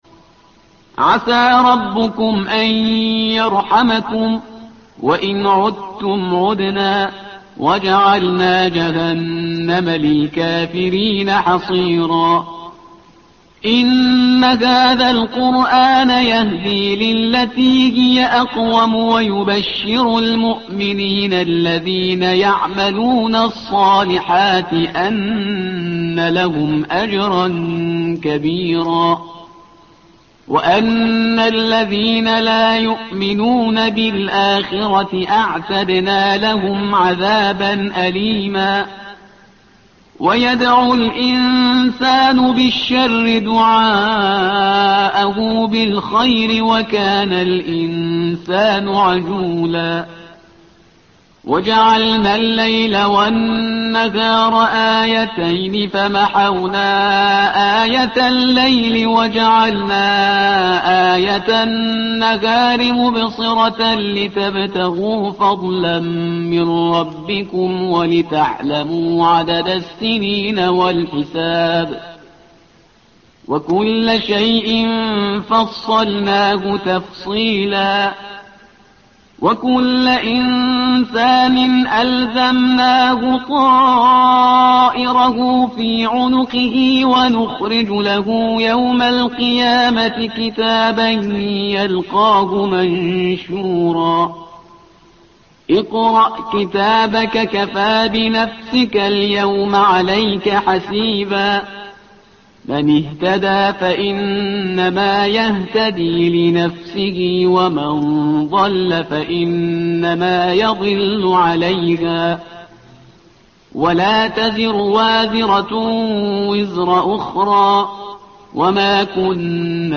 تحميل : الصفحة رقم 283 / القارئ شهريار برهيزكار / القرآن الكريم / موقع يا حسين